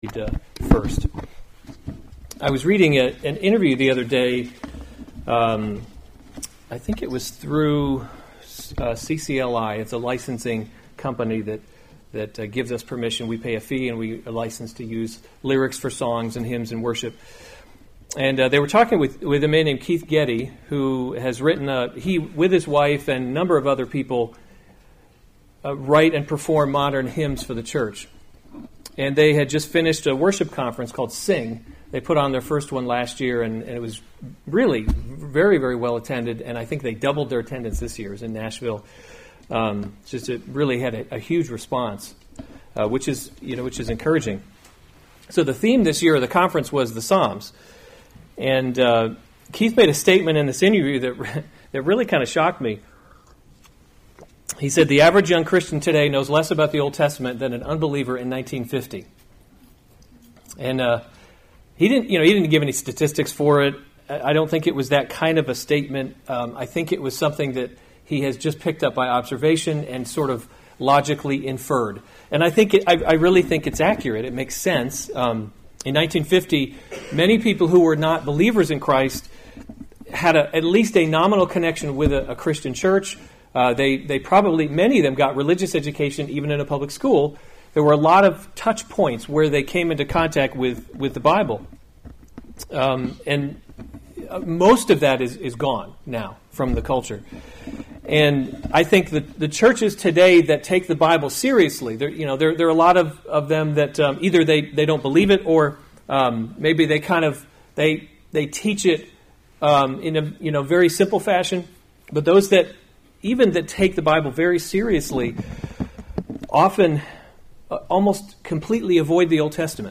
September 22, 2018 1 Kings – Leadership in a Broken World series Weekly Sunday Service Save/Download this sermon 1 Kings 3 Other sermons from 1 Kings Solomon’s Prayer for Wisdom […]